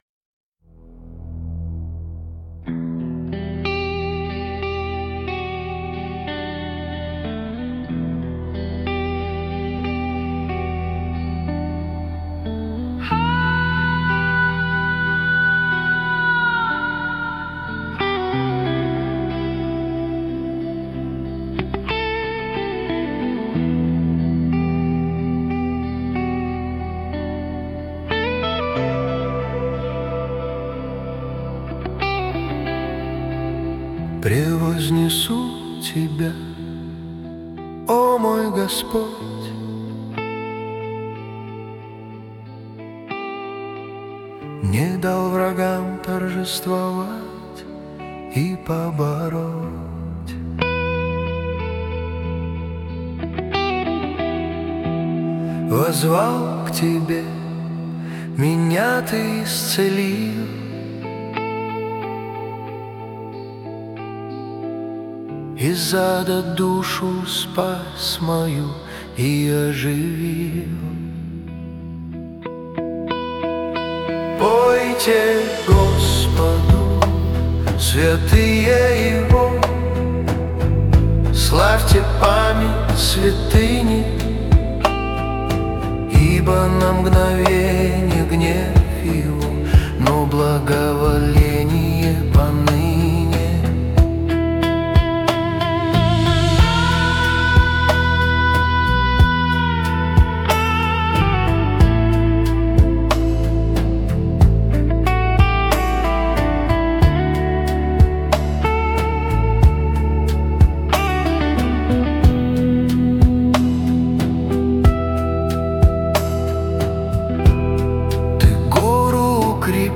песня ai
132 просмотра 617 прослушиваний 59 скачиваний BPM: 92